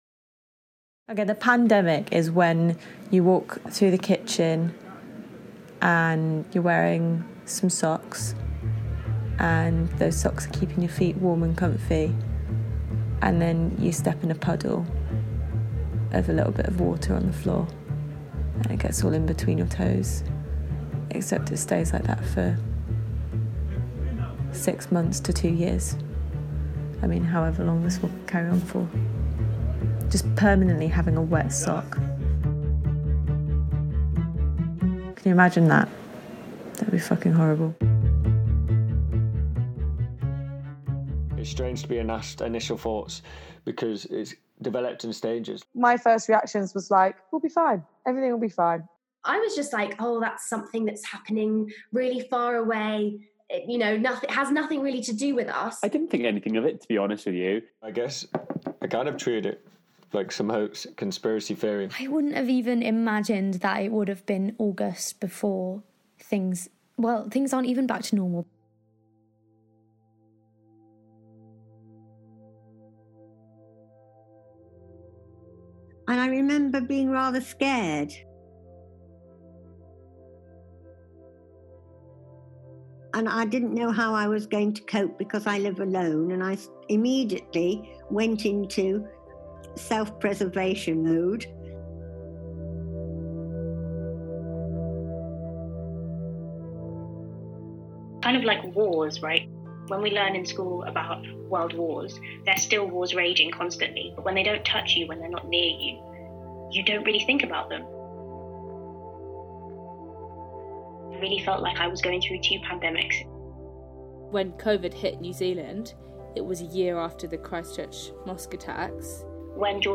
The audio-feature broadcasts 20 voices from the UK and beyond, reflecting on their lives, wellbeing and mental health during the first COVID-19 lockdown. It beautifully combines dialogue, sound and music to highlight the diverse range of emotions experienced by each one of us.